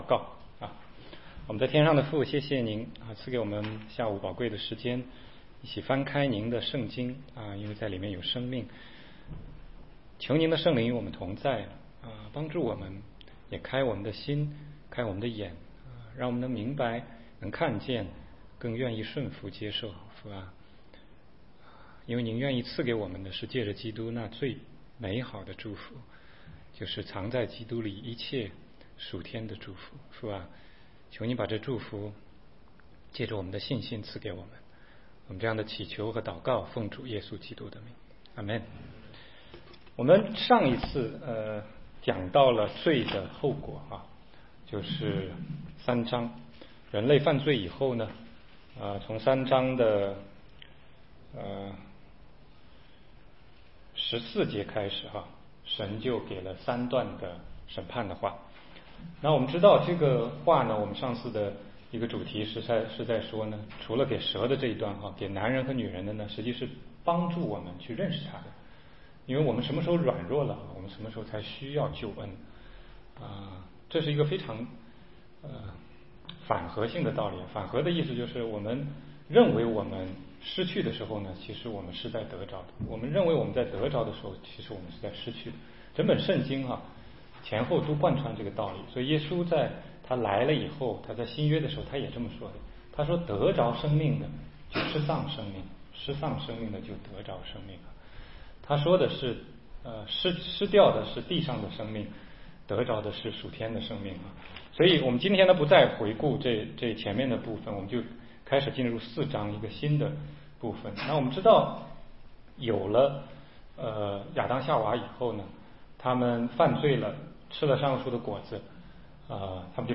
16街讲道录音 - 亚伯和该隐-相信神还是相信自己